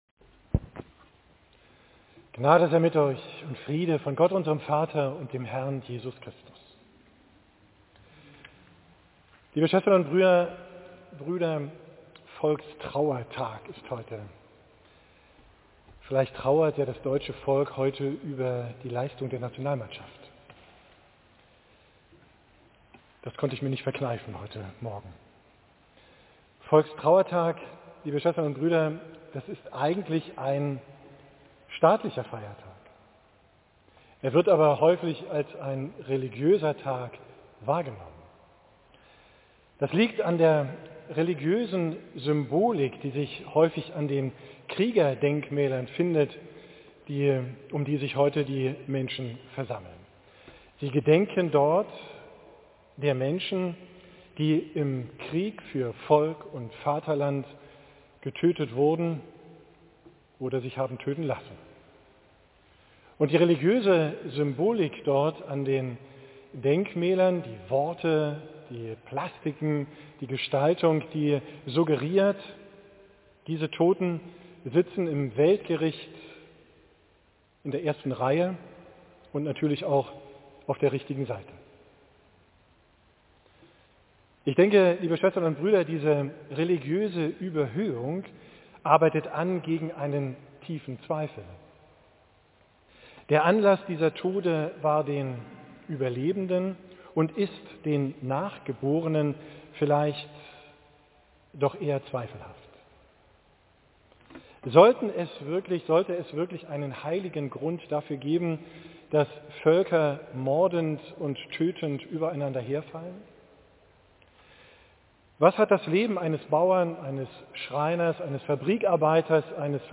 Predigt vom vorletzten Sonntag im Kirchenjahr (Volkstrauertag), 19.